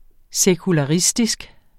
Udtale [ sεkulɑˈʁisdisg ]